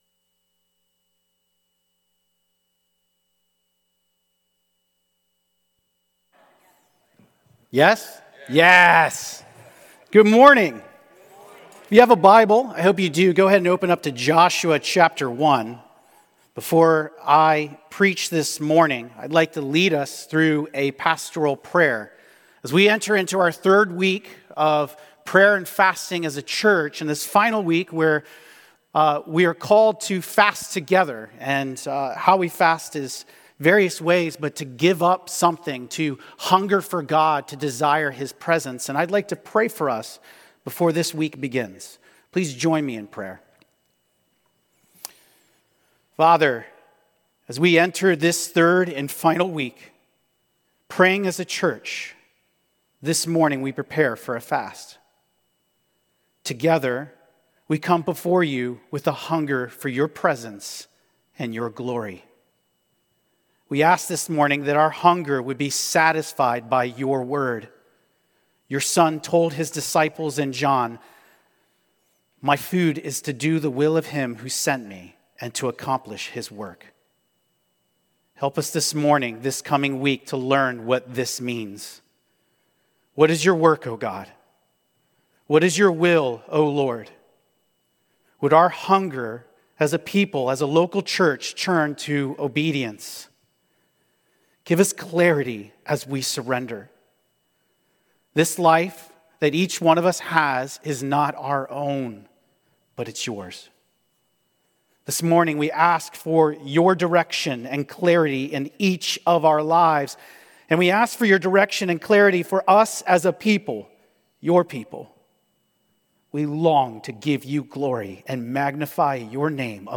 Metro Life Church Sermons Podcast - Joshua 1:10-18 - A Joshua Who Was More | Free Listening on Podbean App